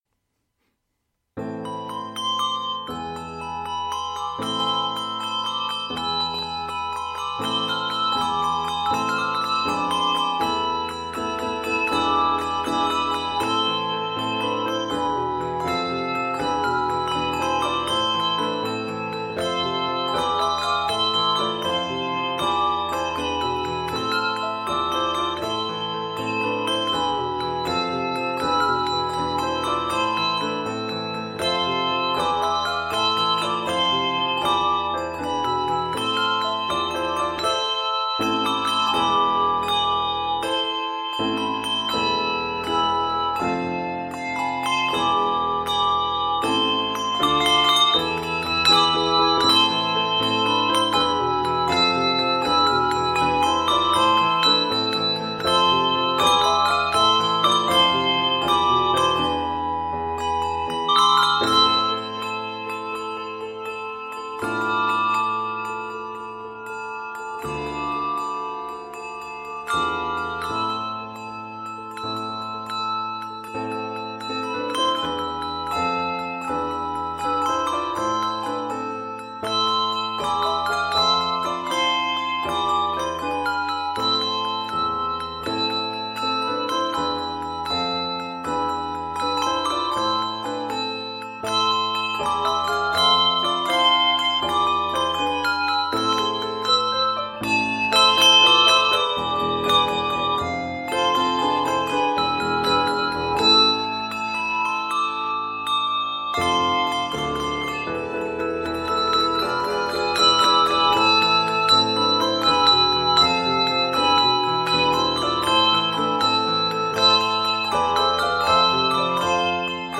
This forceful arrangement